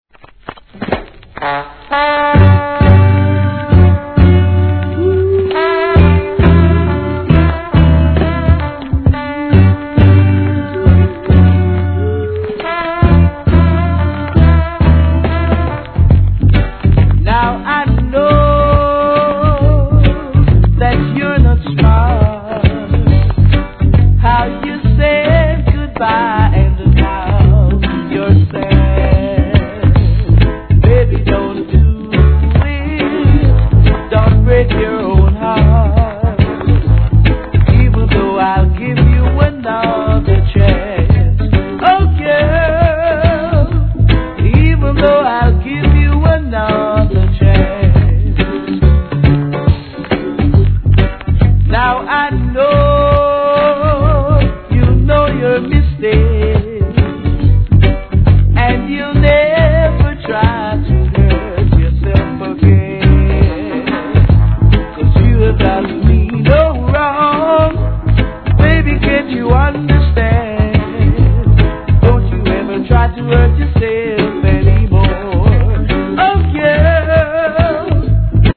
C (終盤で周期的なノイズ)
REGGAE